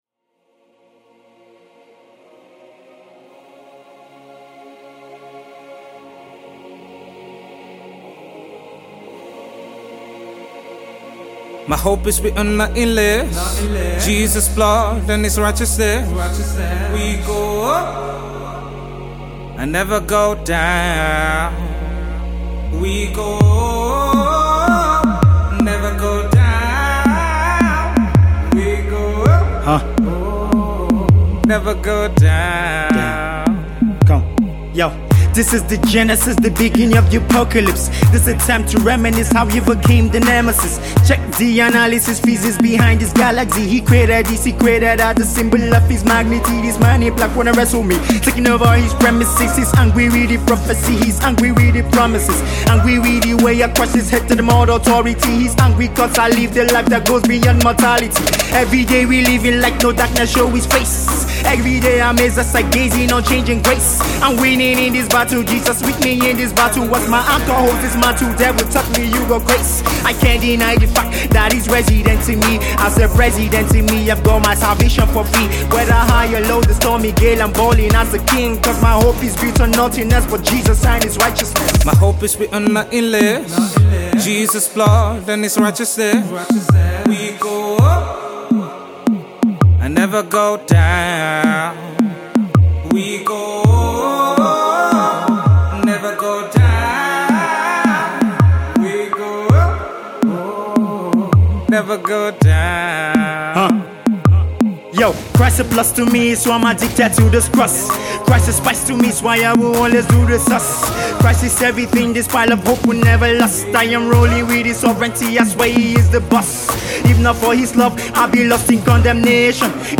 Fast-rising rapper
smooth RnB singer